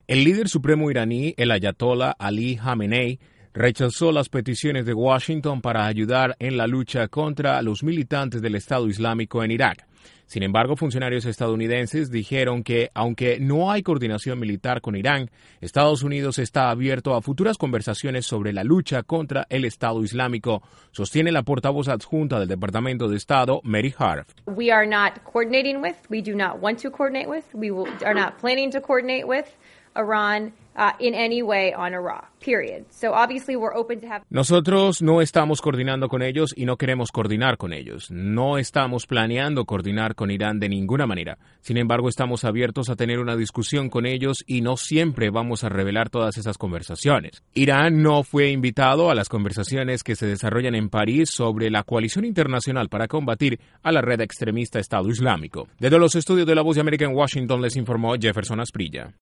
EEUU dispuesto a para futuras conversaciones con Irán sobre la lucha contra militantes de ISIS, pero por ahora no hay ninguna coordinación militar con Teherán. Desde la Voz de América en Washington informa